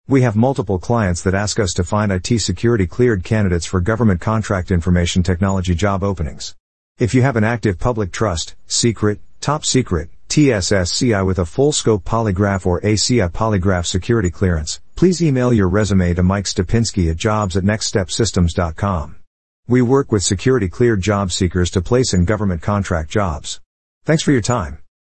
Security Clearance Job Seekers, Listen to Our Artificial Intelligence (AI) on How Our IT Staffing Company Can Help You Find Government and Federal Information Technology Openings
Please take a moment to listen to an audio file about our security clearance IT job services generated by Artificial Intelligence (AI).